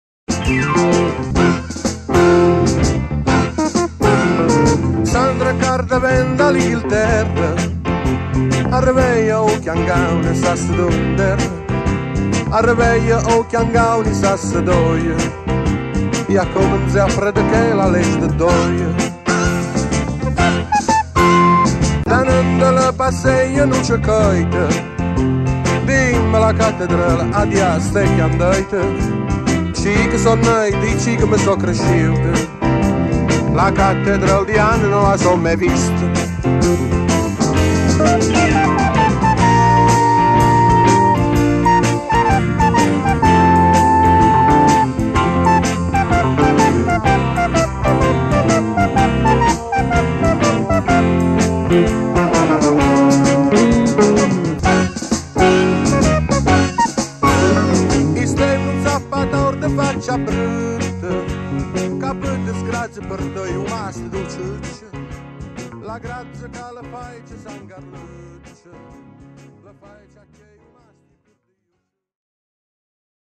oggi viene riproposto in versione rimasterizzata.